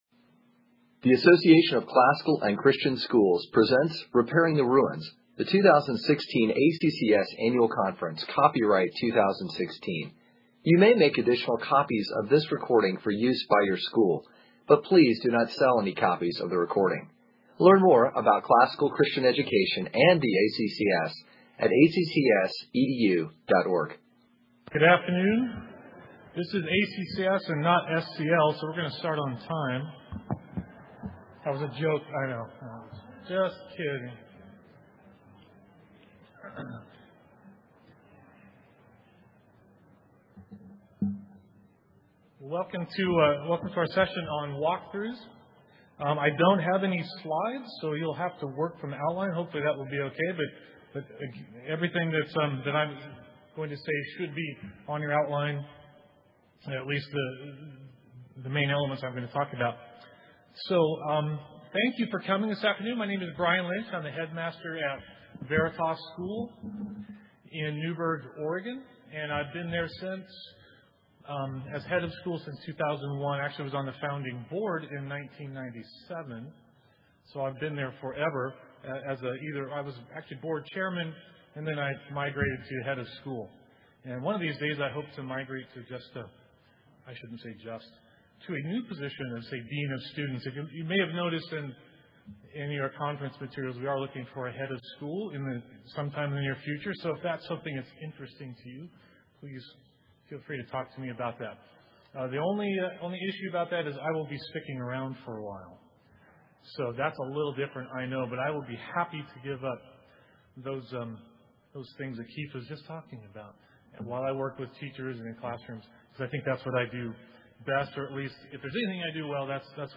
2016 Leaders Day Talk | 47:59:00 | Training & Certification